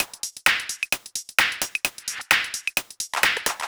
130 Driller Killer No Bd.wav